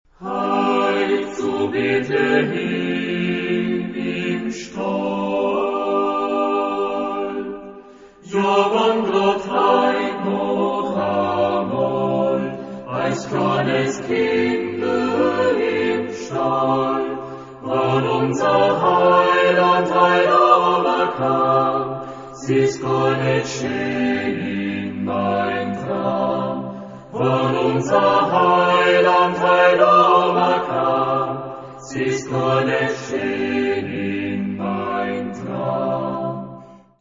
Genre-Style-Forme : Profane ; Populaire
Type de choeur : SAAB OU SATB  (4 voix mixtes )
Tonalité : sol majeur
junge alpenländische Chorlieder & Poesien